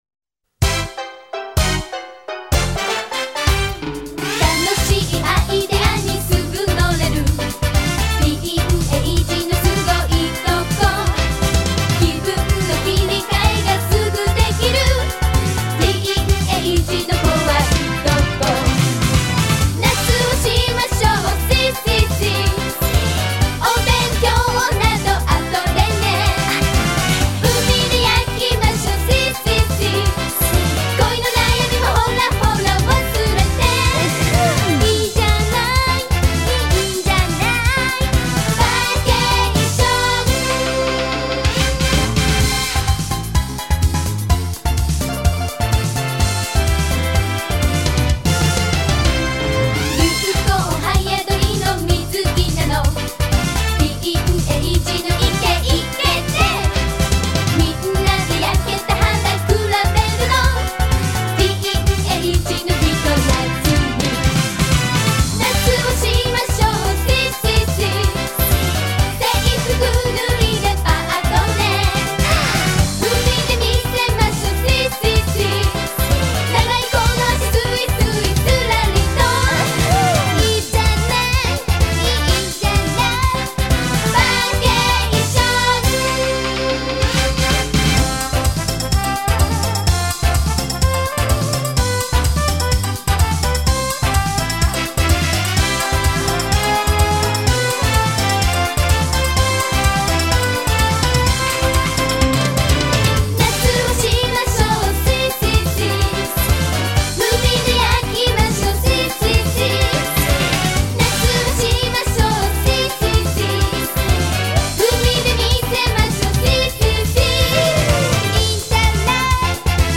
Звучала в мюзикле 1993